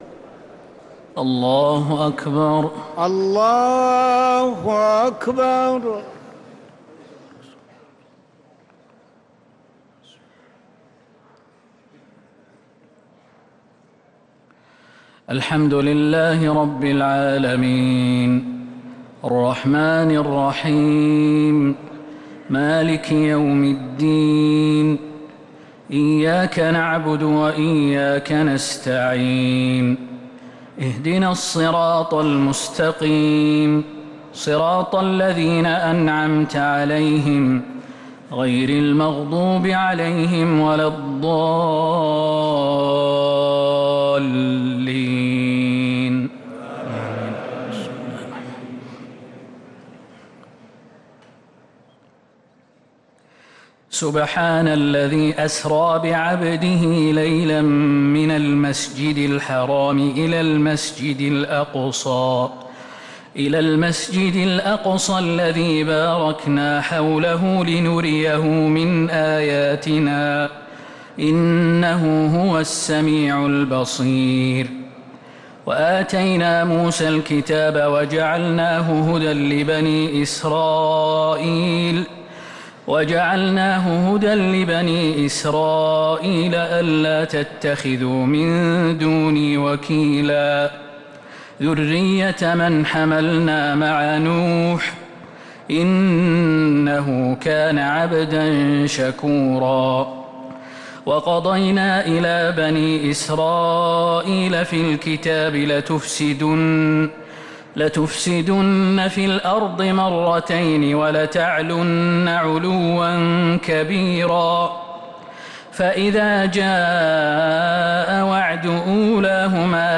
تراويح ليلة 19 رمضان 1447هـ من سورة الإسراء (1-52) | taraweeh 19th night Ramadan 1447H Surah Al-Isra > تراويح الحرم النبوي عام 1447 🕌 > التراويح - تلاوات الحرمين